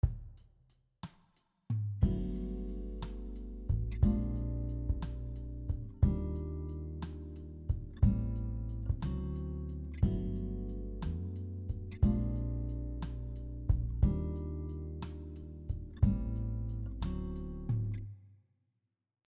Here are some musical examples using shell chords including tabs and audio.
Shell chord progression example 3
This progression is also in the key of G Major with some borrowed chord in measures 2 and 3.